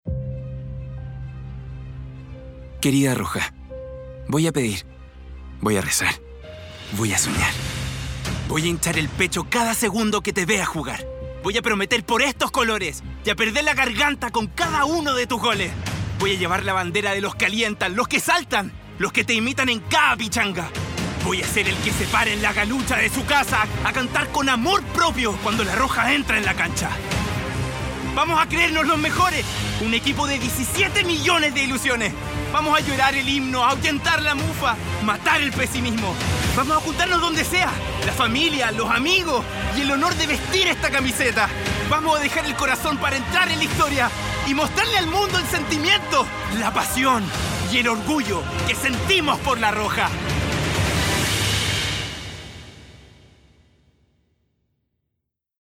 Tengo una fresca, muy versatil y original al momento de grabar.
Sprechprobe: Werbung (Muttersprache):
A young/middle spanish voice who's an Actor and very creative at work time looking for Original ideas.